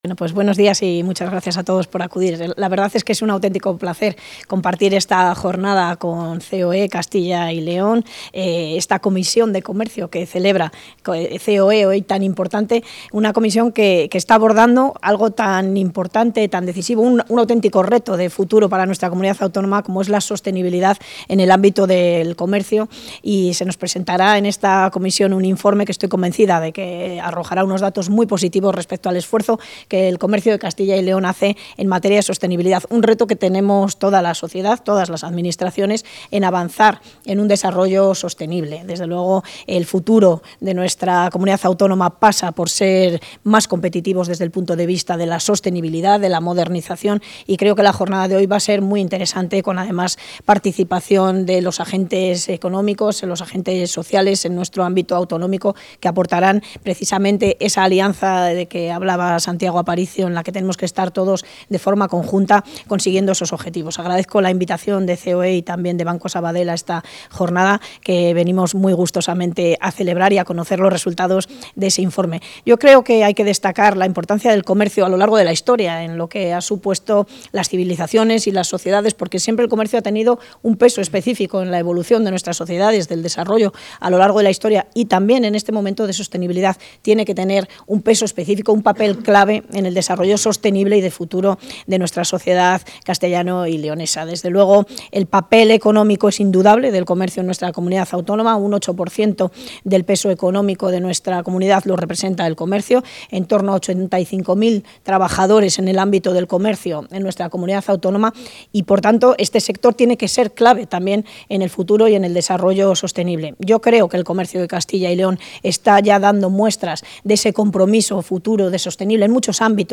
Valoración de la consejera.